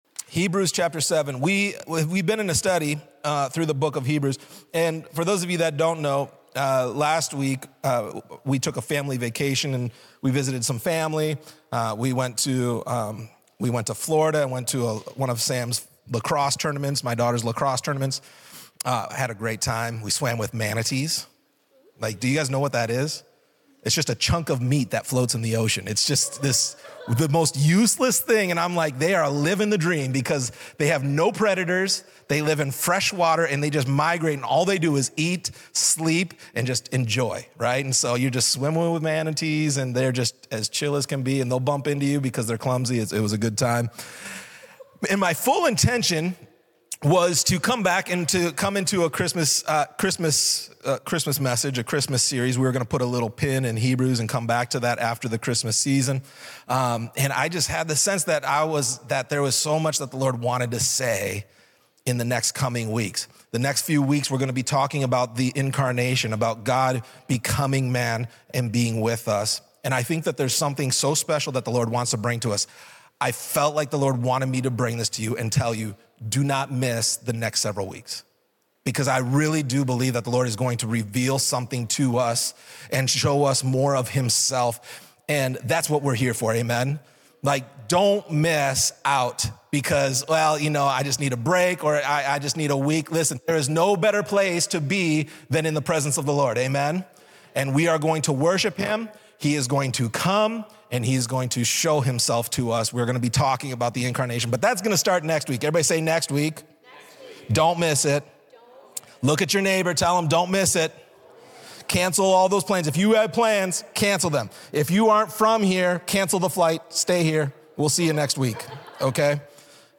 This sermon delves into the biblical promise of entering God's rest, drawn from Psalm 95 and Hebrews 3. Rest is not simply about physical peace, but a deep, inner spiritual rest that comes from trusting in Jesus.